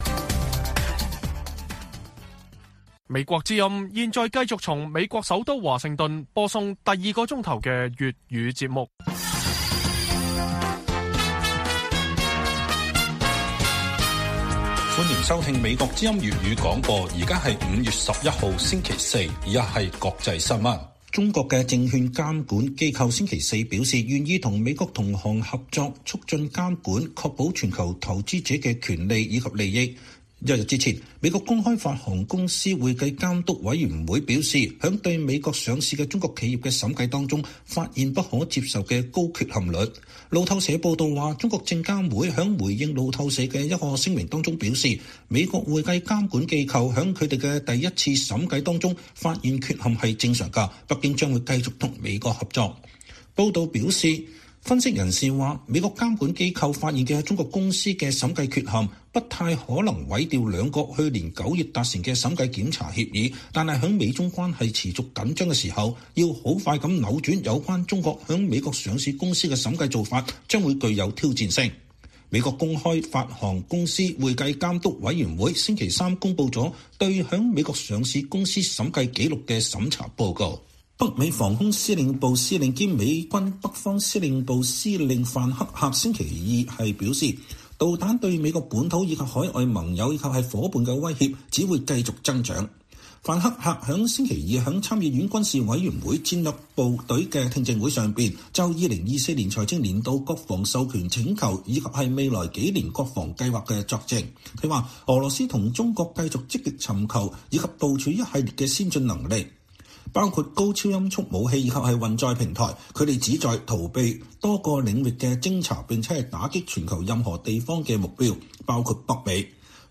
粵語新聞 晚上10-11點: 中國證監會稱中概股審計缺陷多，並願與美國監管機構合作